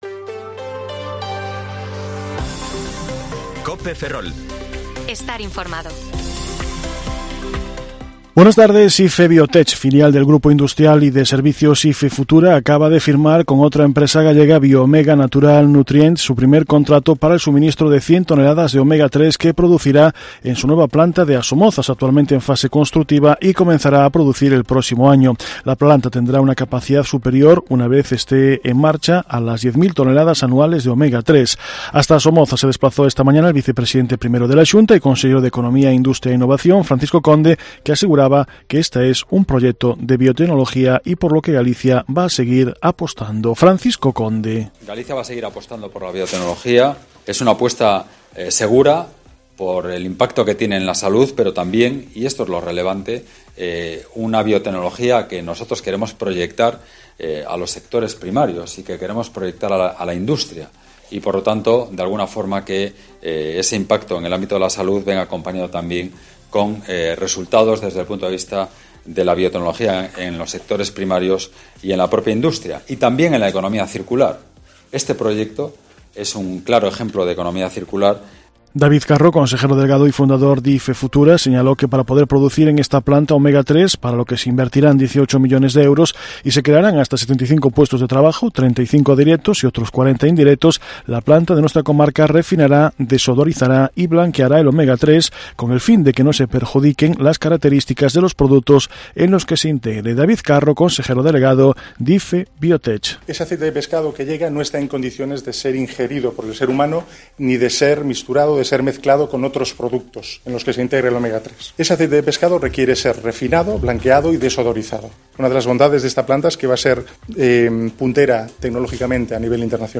Informativo Mediodía COPE Ferrol 4/10/2022 (De 14,20 a 14,30 horas)